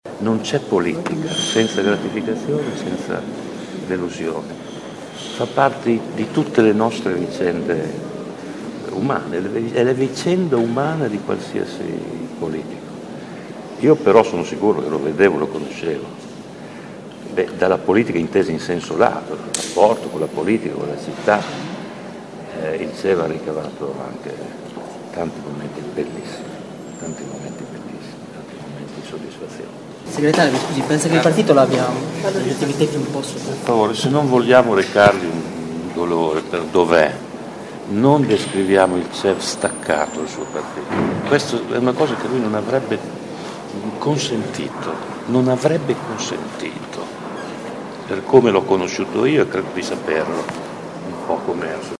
Anche il segretario del Pd Pierluigi Bersani è stato presente alla cerimonia fin dalla prime ore del mattino quando ha fatto il picchetto attorno alla bara.